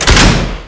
fire_torpedo.wav